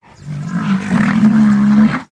Tiếng gầm con Tê Giác
Tiếng động vật 436 lượt xem 10/02/2026
File âm thanh "Tiếng gầm con Tê Giác" thường là một tổ hợp các âm thanh trầm đục và đầy năng lượng. Tê giác không gầm vang như sư tử, nhưng tiếng của chúng là sự pha trộn giữa tiếng "gầm gừ" (growling) sâu từ lồng ngực, tiếng "rít" (snorting) qua mũi cực mạnh và đôi khi là tiếng "hú hét" (shrieking) khi chúng bị kích động hoặc tranh giành lãnh thổ. Âm thanh này toát lên vẻ thô ráp, mạnh mẽ và vô cùng nặng nề.